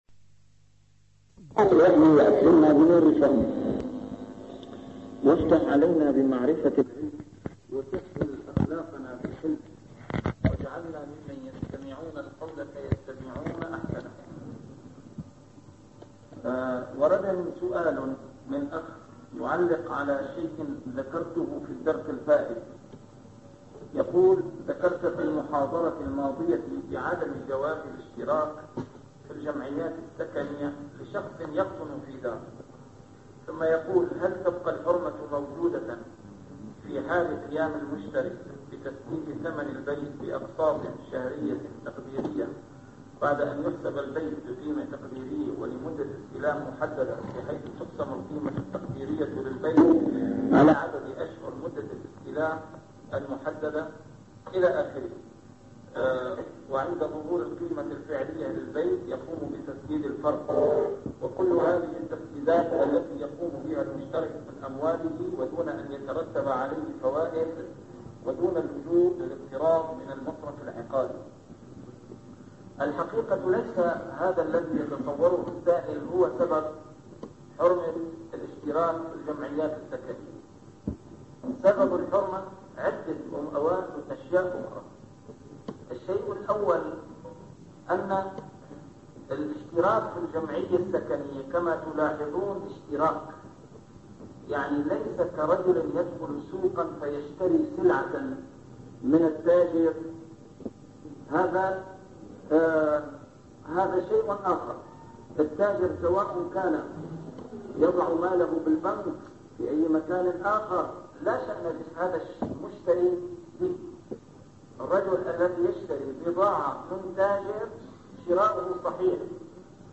A MARTYR SCHOLAR: IMAM MUHAMMAD SAEED RAMADAN AL-BOUTI - الدروس العلمية - شرح الأحاديث الأربعين النووية - تتمة شرح الحديث العاشر: حديث أبي هريرة (إنَّ اللهَ طيبٌ لا يقبل إلا طيباً) 43